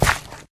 Sound / Minecraft / step / gravel1